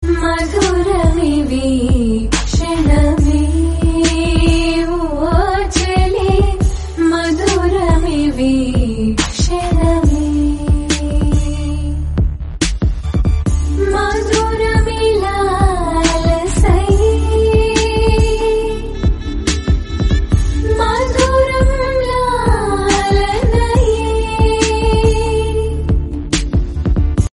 melody ringtone romantic ringtone